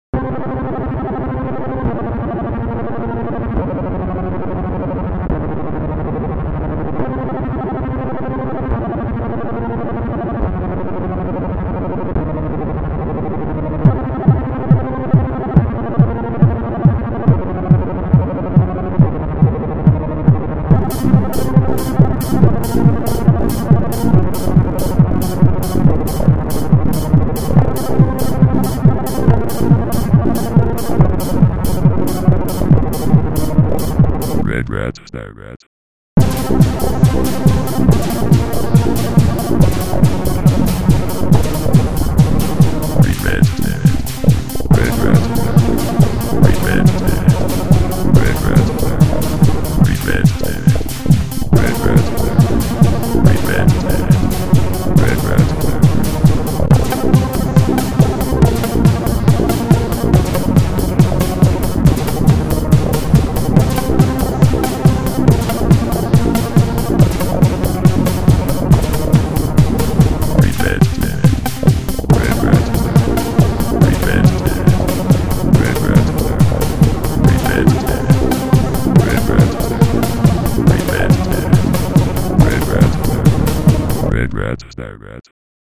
Regrat techno